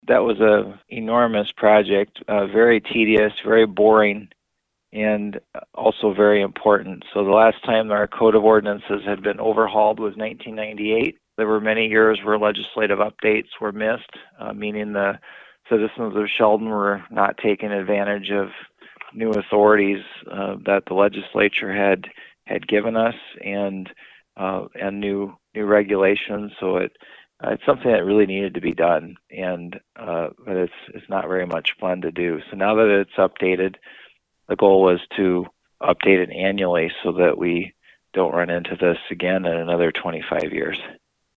The council was able to finish up the work with the code of ordinances. City Manager Sam Kooiker tells us more.